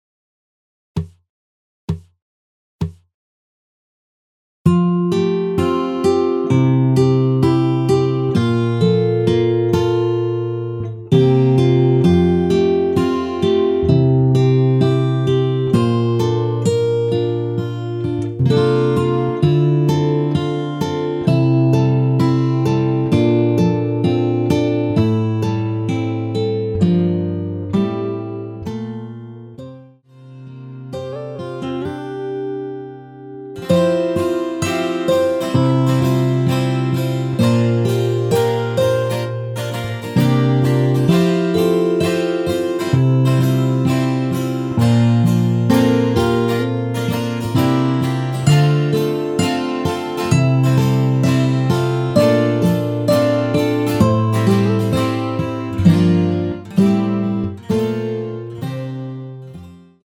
전주없이 시작 하는 곡이라 카운트 넣어 놓았습니다.(미리듣기 참조)
◈ 곡명 옆 (-1)은 반음 내림, (+1)은 반음 올림 입니다.
앞부분30초, 뒷부분30초씩 편집해서 올려 드리고 있습니다.